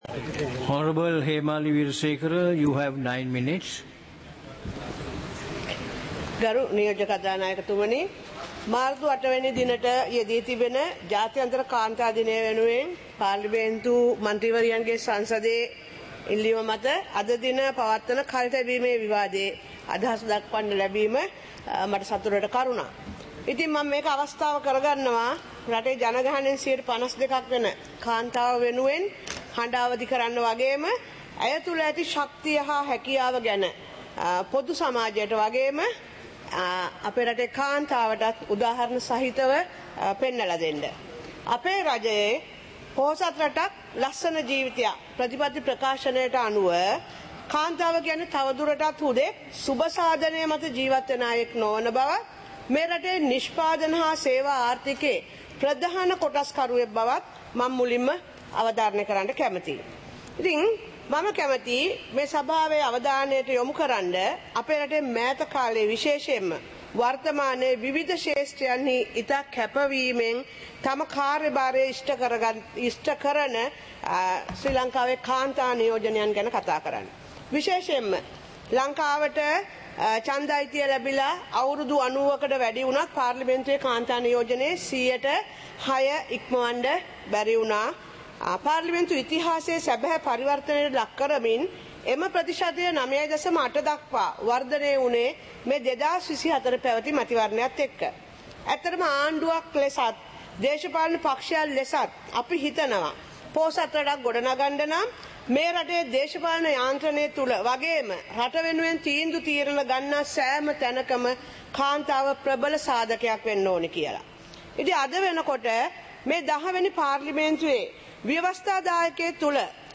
Proceedings of the House (2026-03-05)
Parliament Live - Recorded